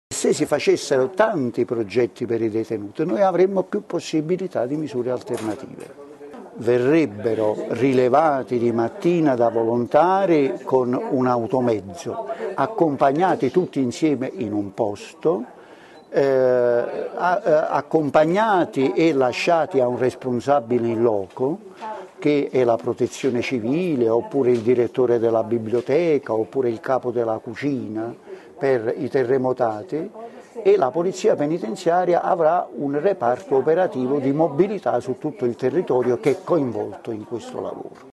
Il presidente del tribunale di sorveglianza di Bologna Francesco Maisto spiega le modalità con cui i detenuti lavoreranno: “Verranno prelevati di mattina da volontari con un automezzo, accompagnati e lasciati a un responsabile in loco e la Polizia penitenziaria avrà un reparto operativo di mobilità su tutto il territorio coinvolto in questo lavoro”.